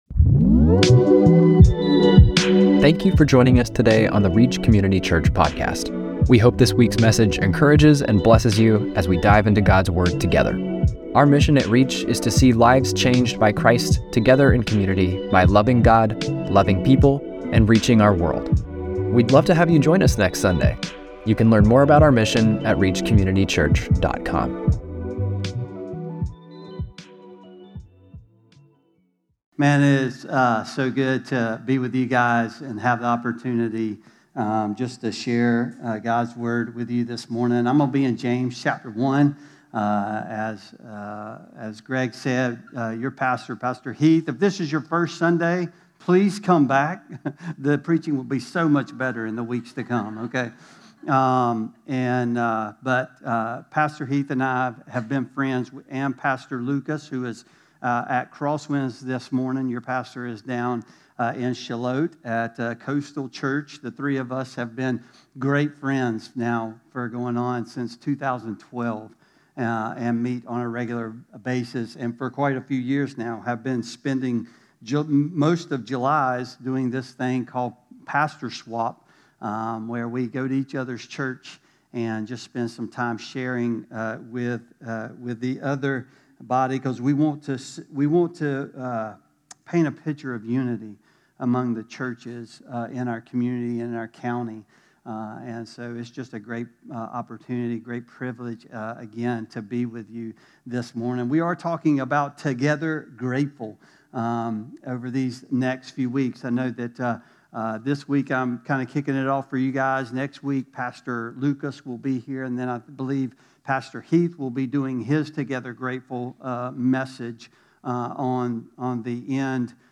7-13-25-Sermon.mp3